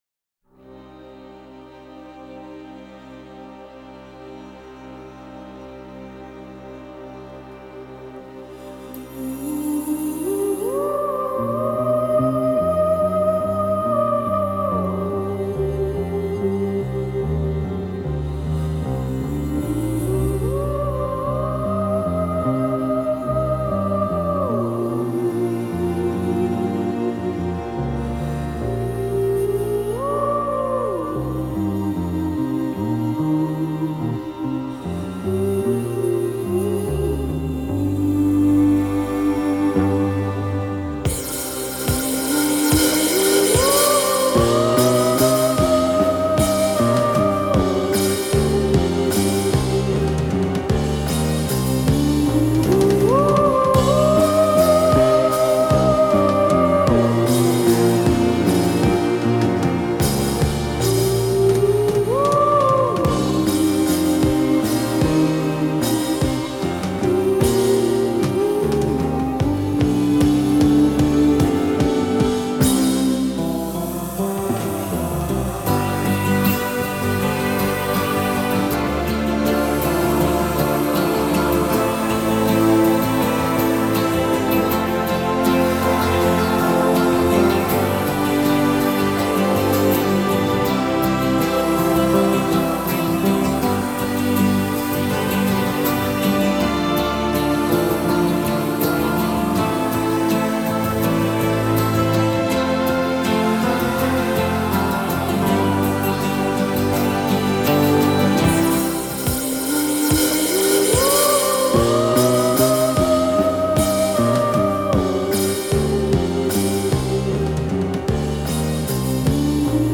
В саундтреке есть вокализ и мелодия без вокала.